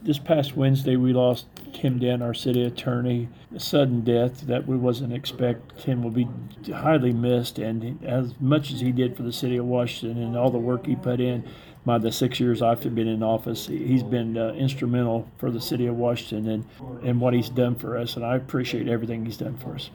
At the end of the meeting, the mayor spoke about Tim Dant’s service to his hometown.
mayor-rhoads-on-tim-dant.mp3